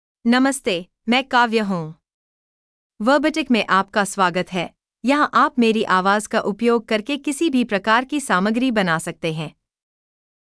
Kavya — Female Hindi (India) AI Voice | TTS, Voice Cloning & Video | Verbatik AI
Voice sample
Listen to Kavya's female Hindi voice.
Female
Kavya delivers clear pronunciation with authentic India Hindi intonation, making your content sound professionally produced.